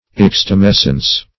Search Result for " extumescence" : The Collaborative International Dictionary of English v.0.48: Extumescence \Ex`tu*mes"cence\, n. [L. ex.